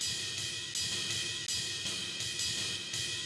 RIDE_LOOP_6.wav